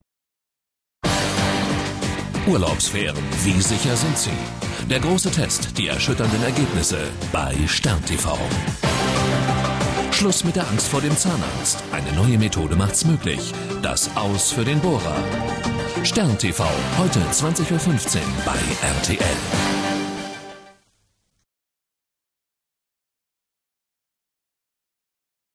Sprechprobe: Werbung (Muttersprache):
German voice over artist with more than 30 years of experience.